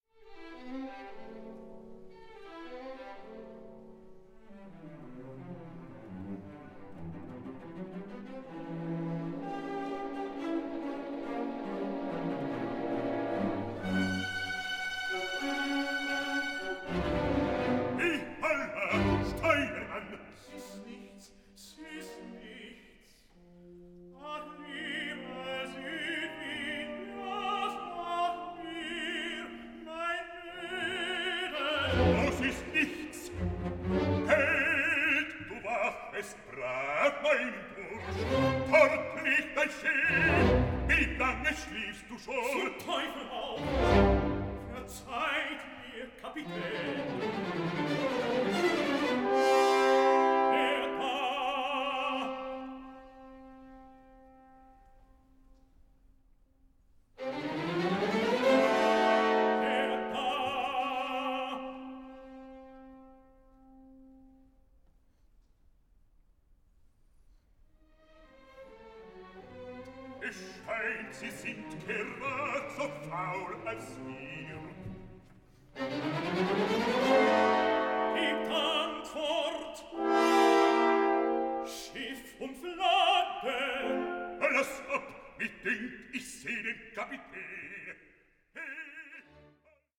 3. Szene, Duett und Chor